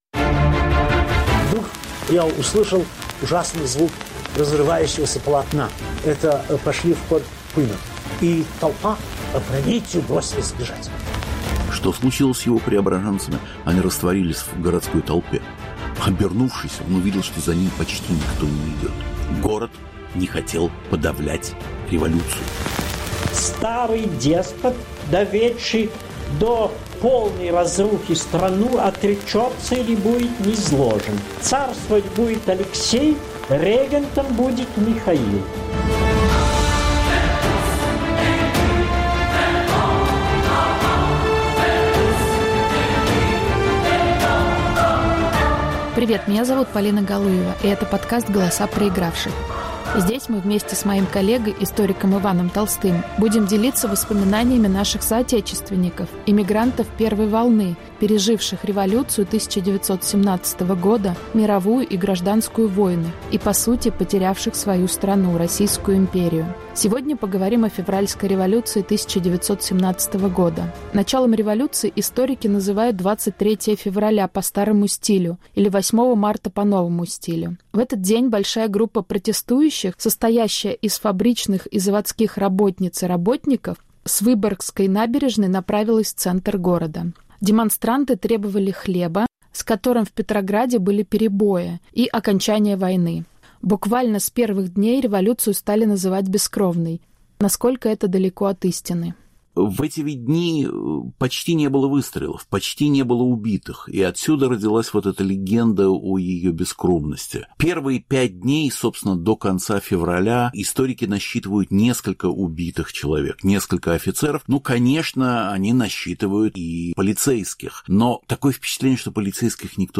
Рассказывают свидетели революционного Петрограда. Повтор эфира от 20 ноября 2022 года.